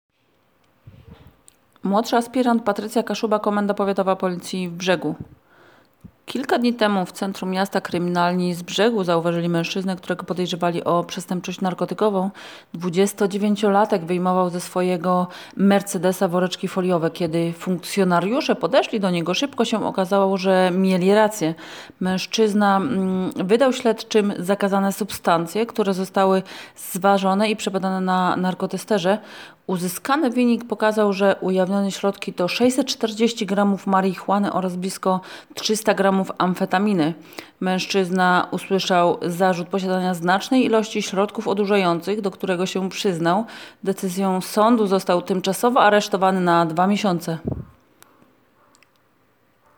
Nagranie audio Wypowiedź - mł. asp.